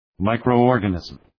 Προφορά
{,maıkrəʋ’ɔ:rgə,nızm}